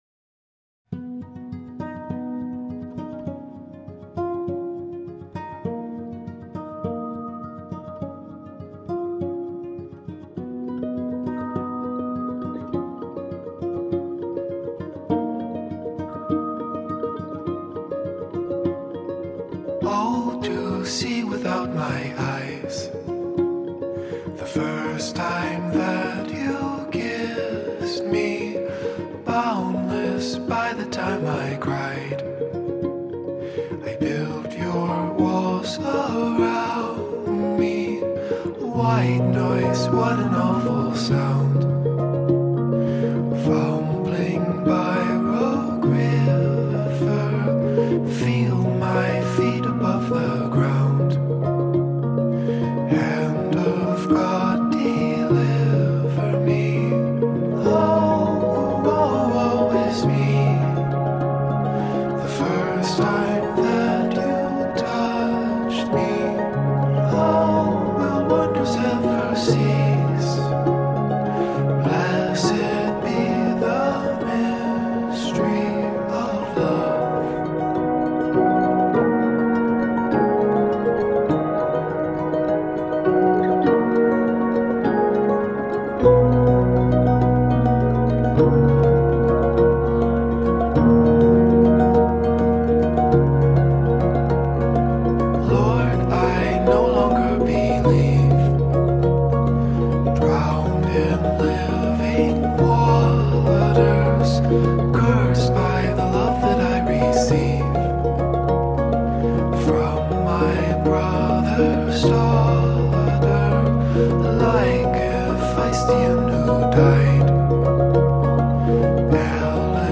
Track4_Soothing_Instrumental.mp3